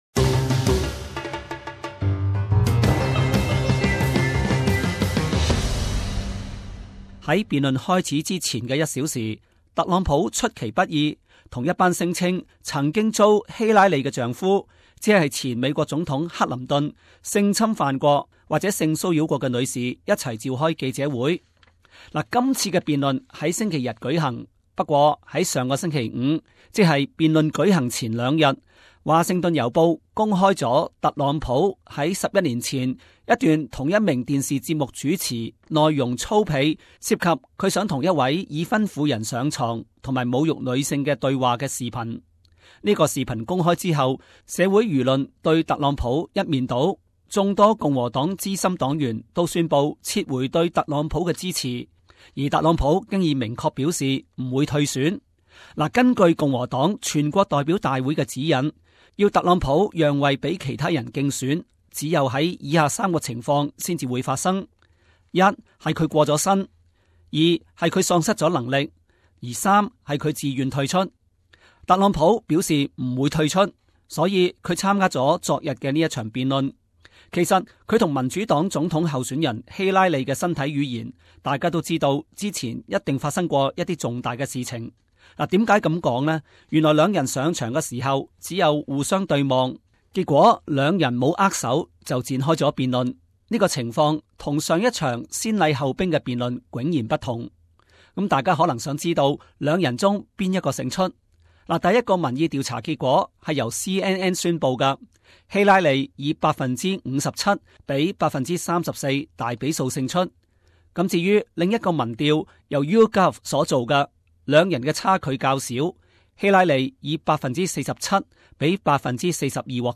SBS广东话播客